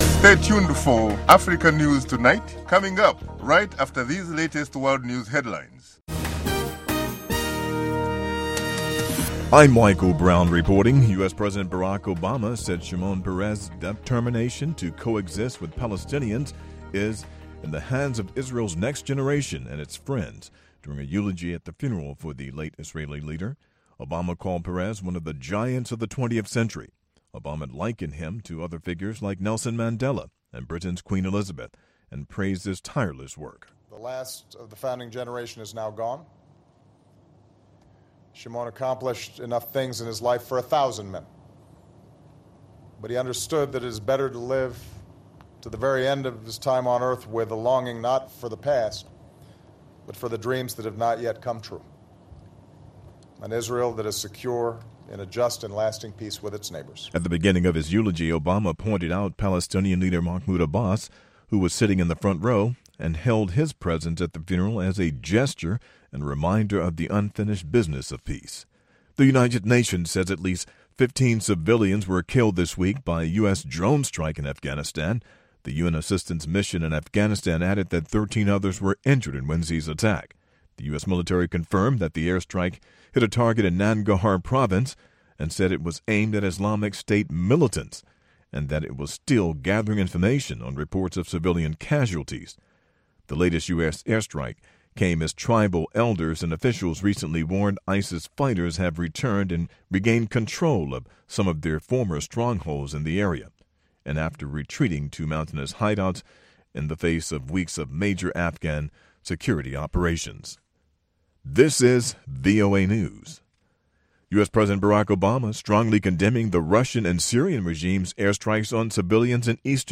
2 Minute Newscast